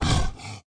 Npc Raccoon Run Sound Effect
npc-raccoon-run-2.mp3